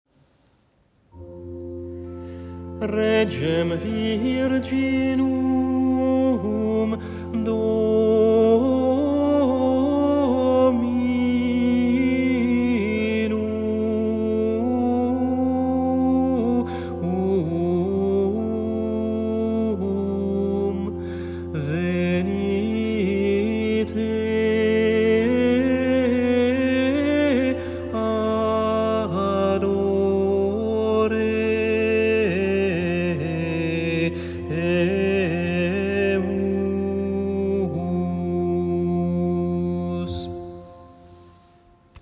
Antienne invitatoire (solennités & fêtes) : Regem virginum [partition LT]